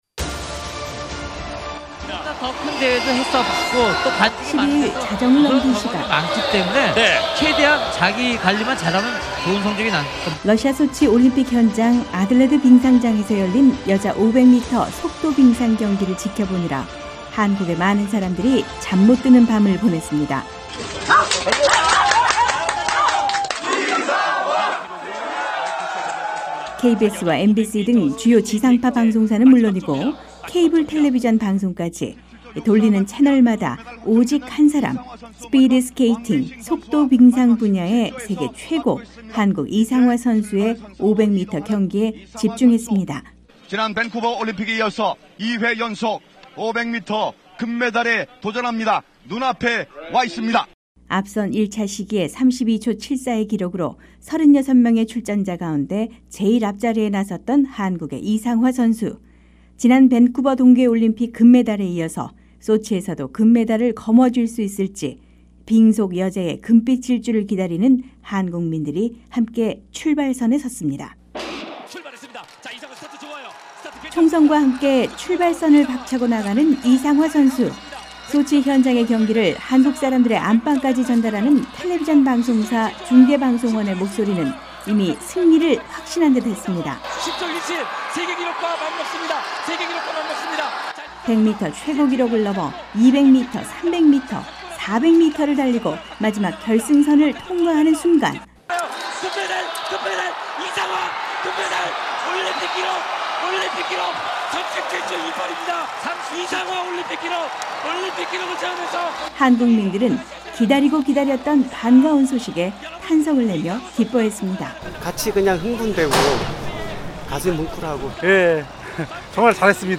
한국사회의 이모저모를 전해드리는 ‘안녕하세요 서울입니다’ 순서입니다. 오늘 소치올림픽에서 첫 금메달을 따내 응원열기가 달아오를 한국사람들의 목소리를 전해드립니다.